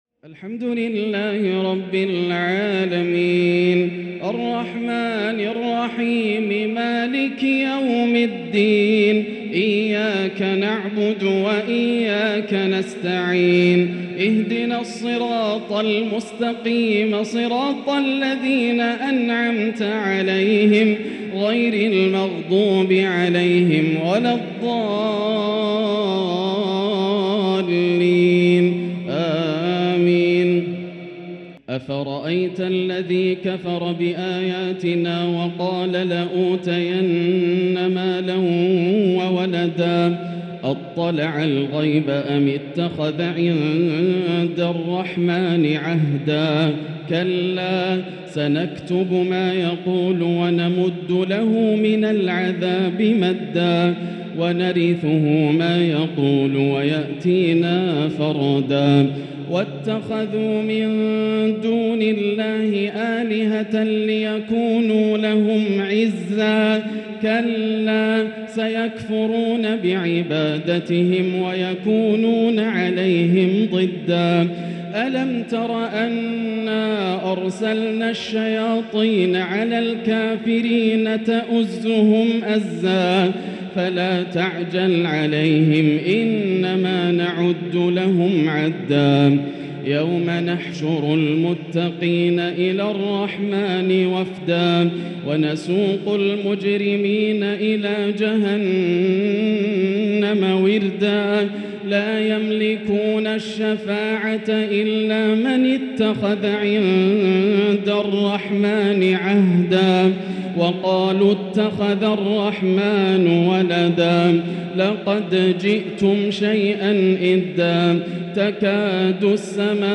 تراويح ليلة 21 رمضان 1444هـ من سورتي مريم (77-98) و طه (1-76) Taraweeh 21 th night Ramadan 1444H Surah Maryam and Taa-Haa > تراويح الحرم المكي عام 1444 🕋 > التراويح - تلاوات الحرمين